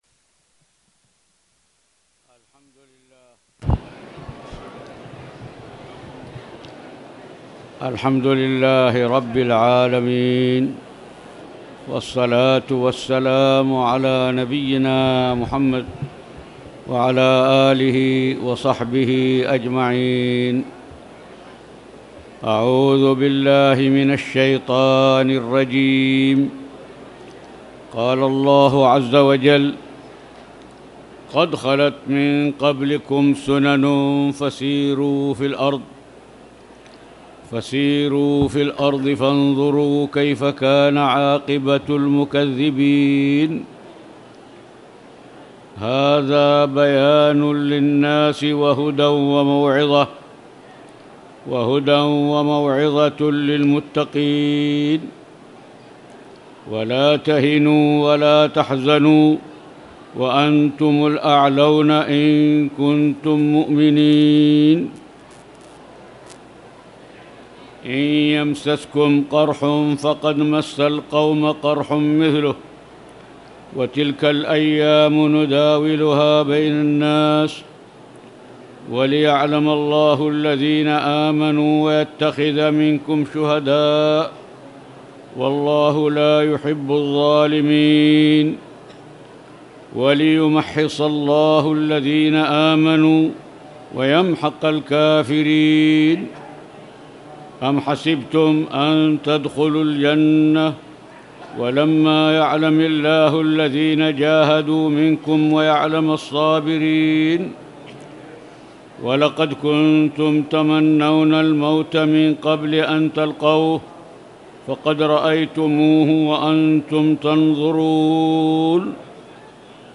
تاريخ النشر ٢٥ رجب ١٤٣٨ هـ المكان: المسجد الحرام الشيخ